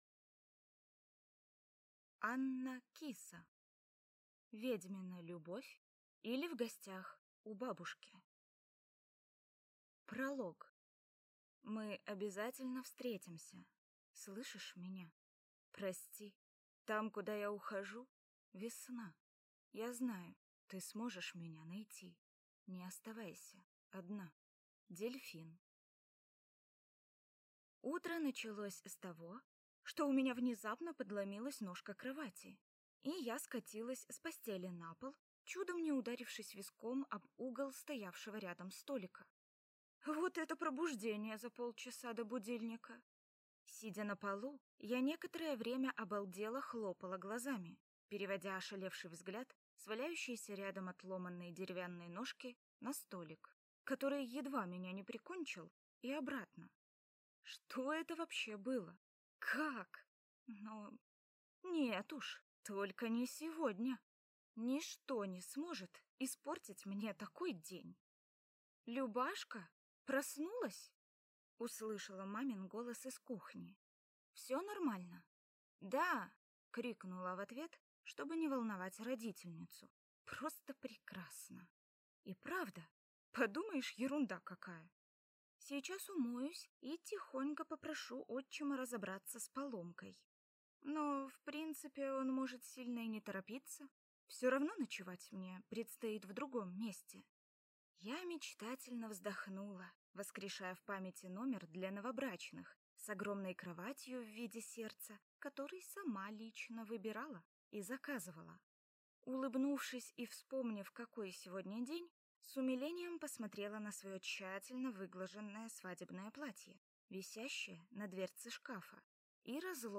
Аудиокнига Ведьмина любовь, или В гостях у бабушки | Библиотека аудиокниг